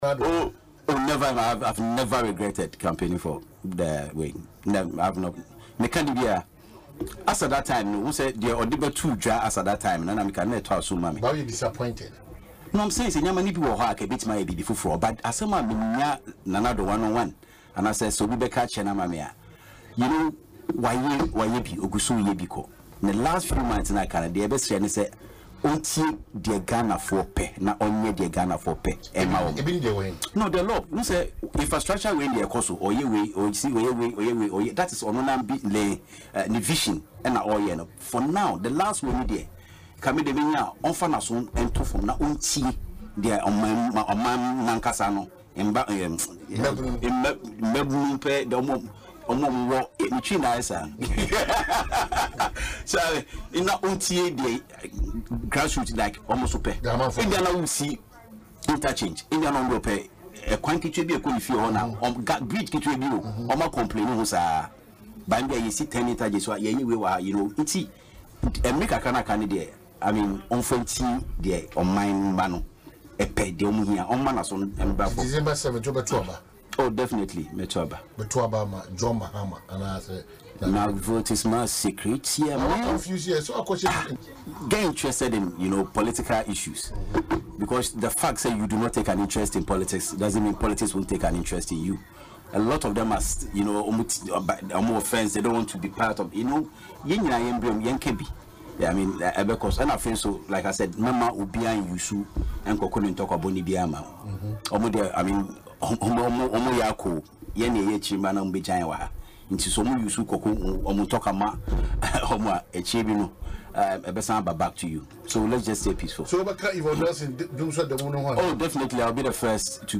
He made this known in an interview on Asempa FM’s Ekosii Sen programme Thursday.